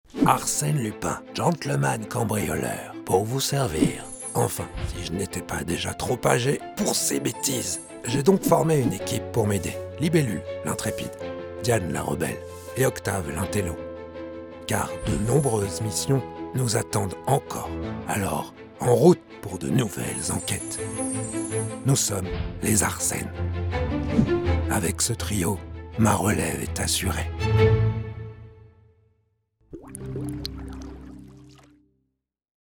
Pub / Book Trailer (Scrox, Brax et fin du monde)
Voix off